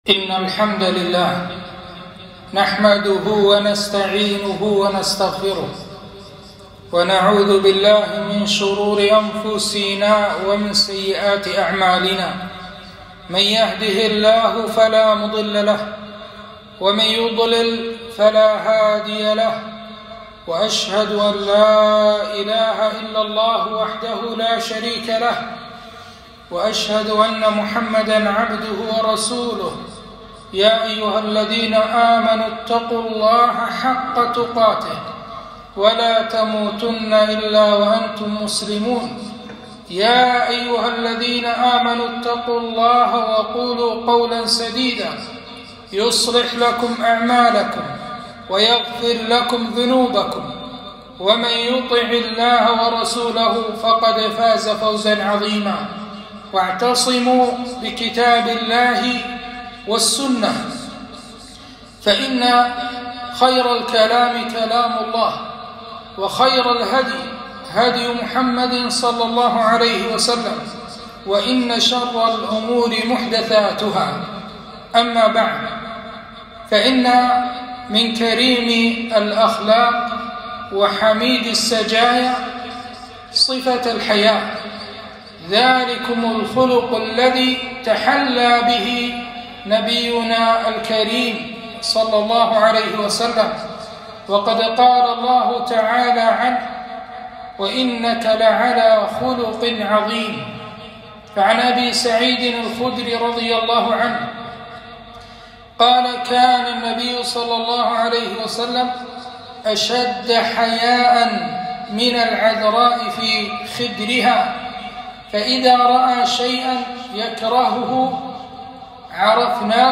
خطبة - إن الله يحب الحياء والستر